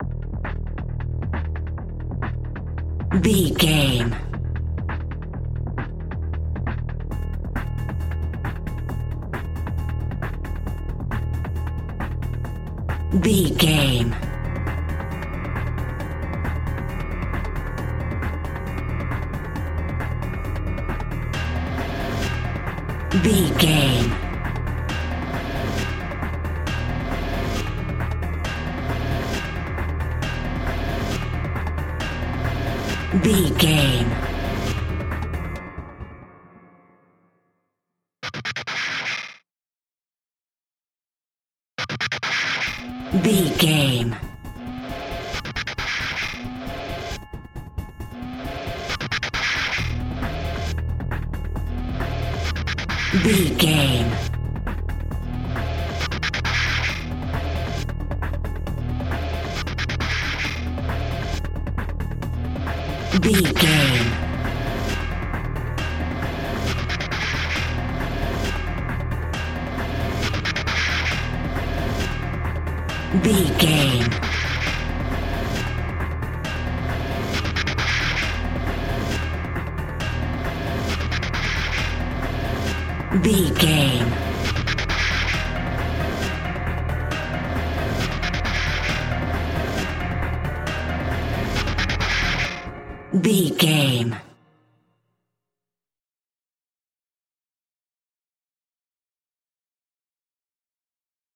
Aeolian/Minor
Fast
tension
ominous
eerie
driving
drum machine